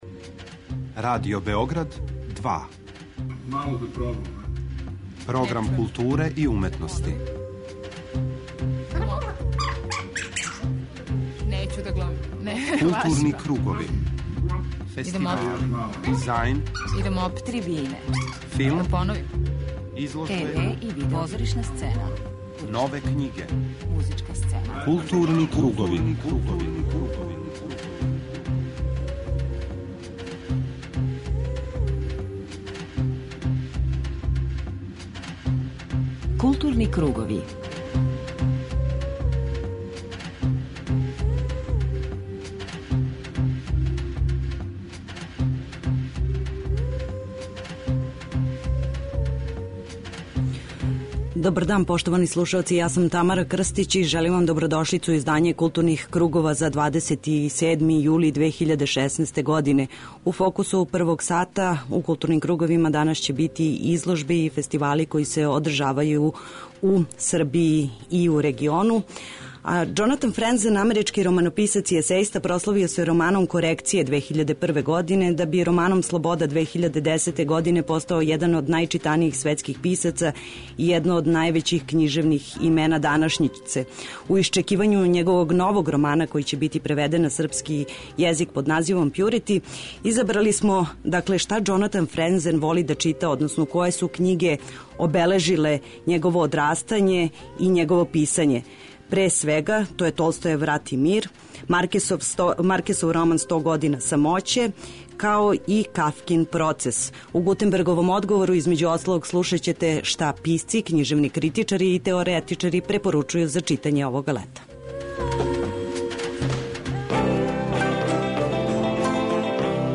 преузми : 40.87 MB Културни кругови Autor: Група аутора Централна културно-уметничка емисија Радио Београда 2.